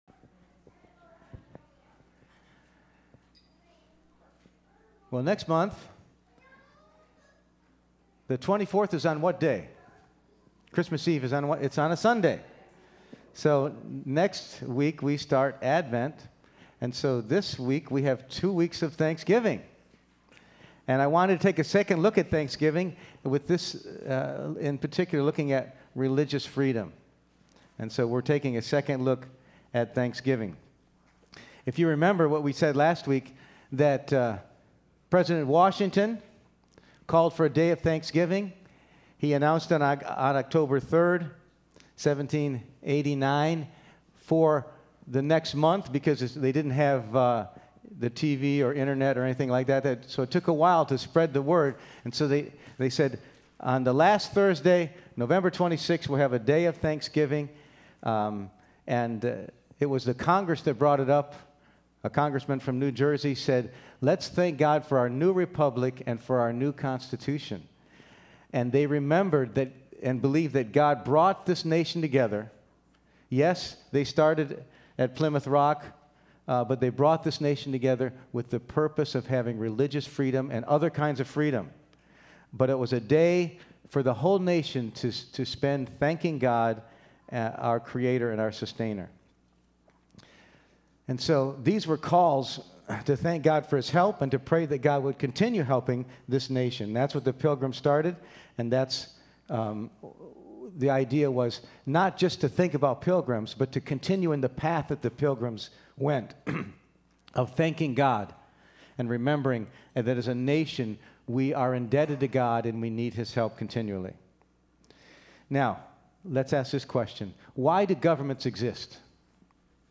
Preacher
Service Type: Sunday Morning